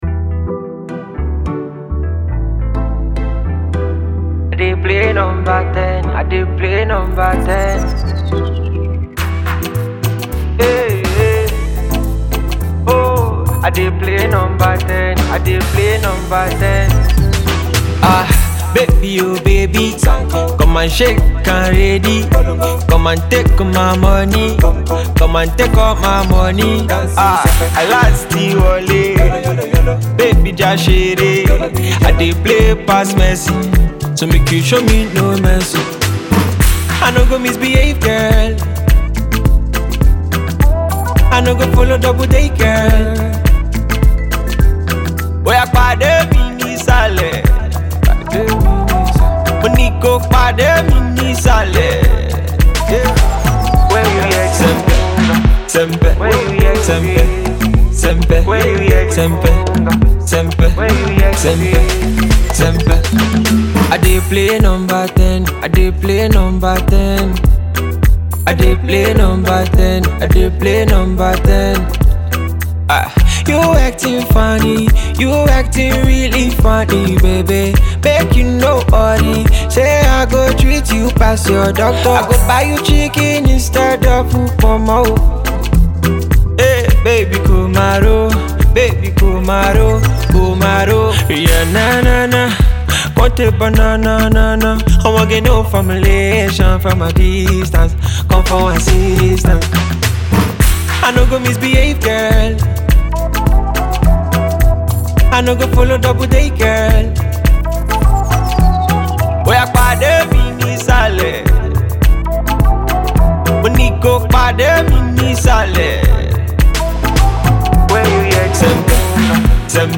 Dance Hall